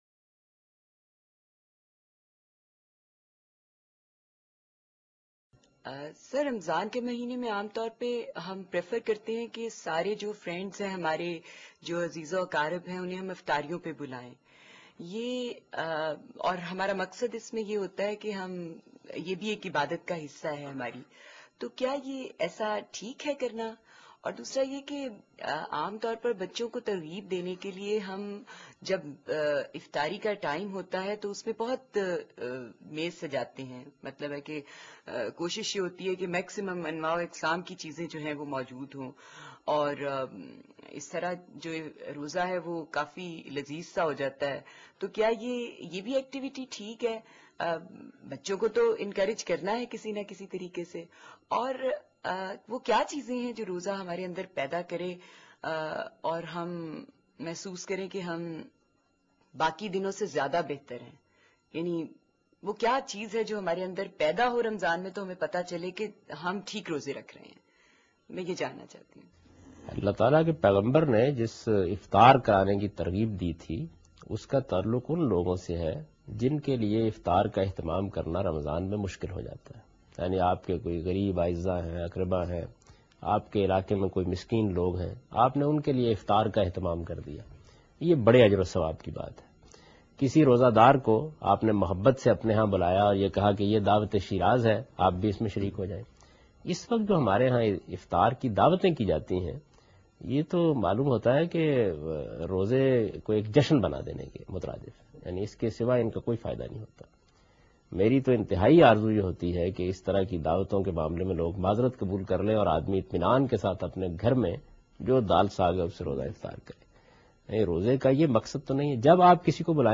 Category: Reflections / Questions_Answers /
جاوید احمد غامدی رمضان میں شاہانہ افطاریاں کے بارے میں بیان کررہے ہیں